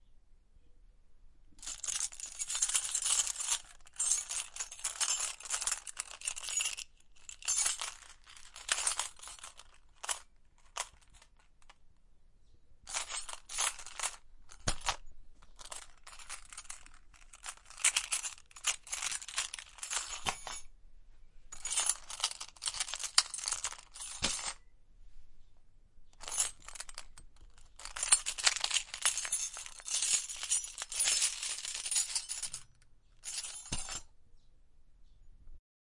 KEYS JINGLING
描述：Car and house keys jingling.
标签： housekeys carkeys jingling keys
声道立体声